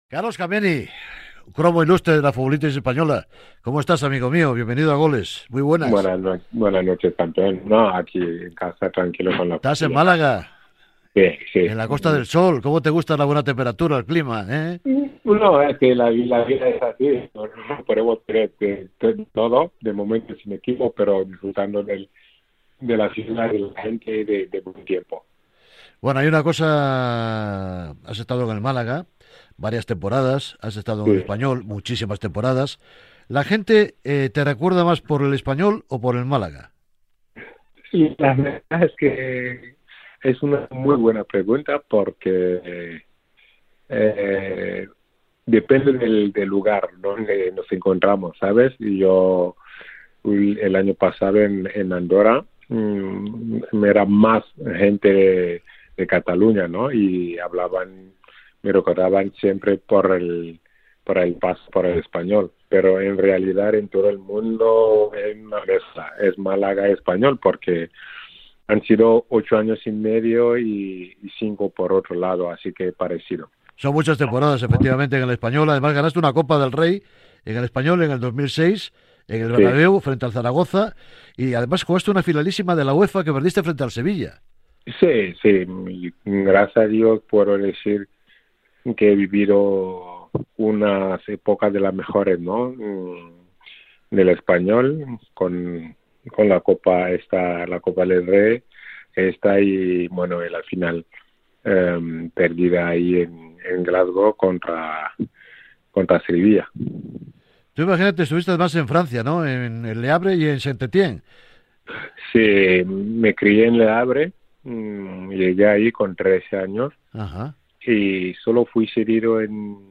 ENTREVISTA-CARLOS-KAMENI.mp3